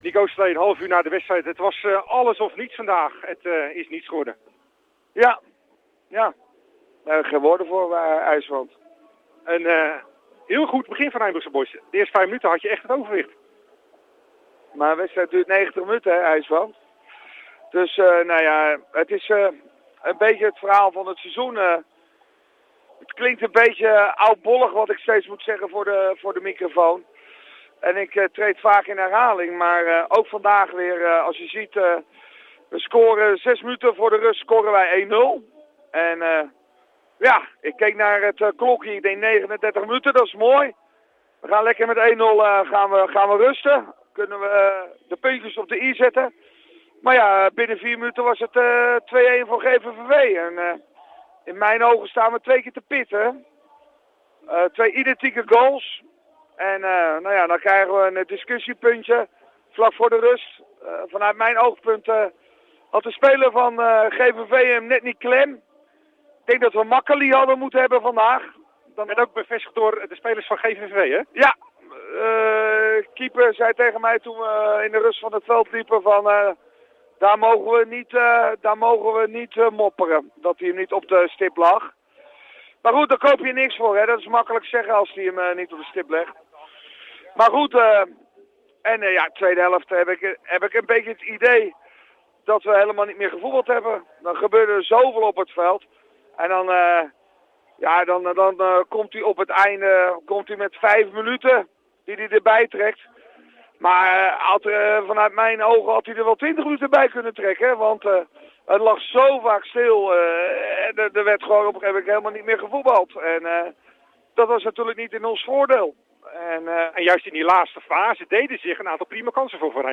AUDIO: Interview